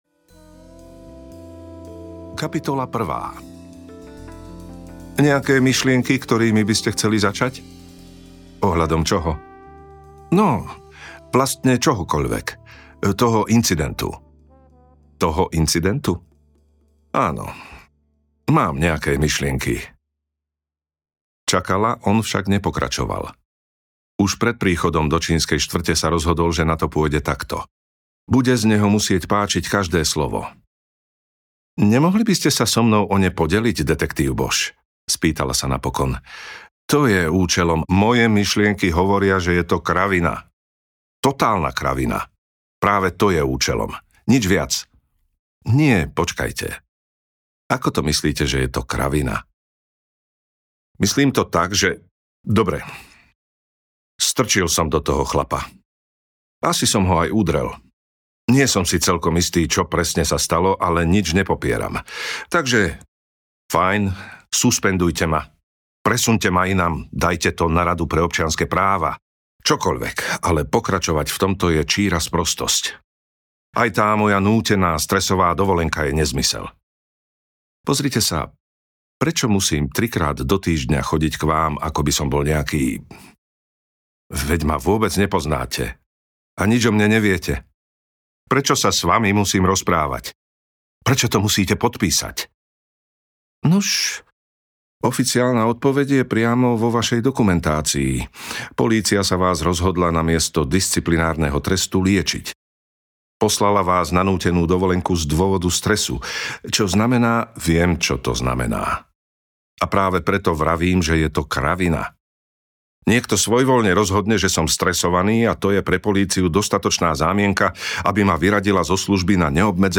Posledný kojot audiokniha
Ukázka z knihy